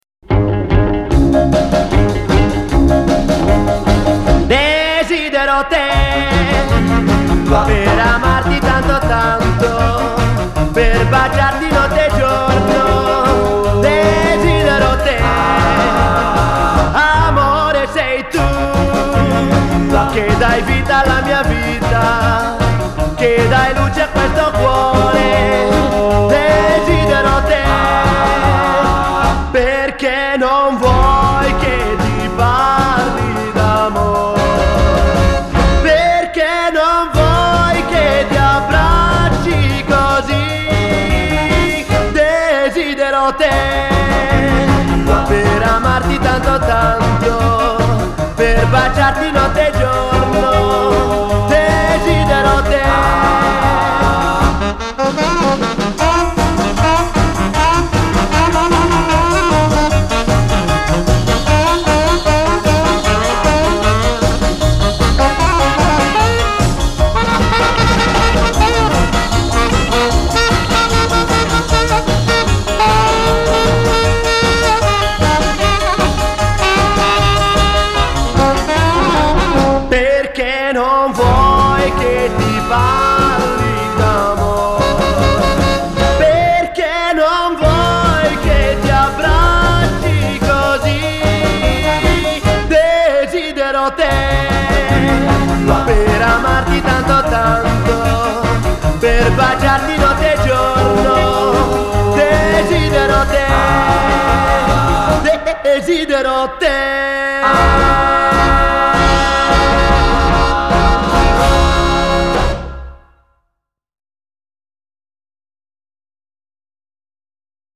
brani originali anni 60
Canzoni d'amore indimenticabili